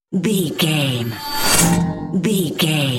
Sci fi appear whoosh debris
Sound Effects
futuristic
whoosh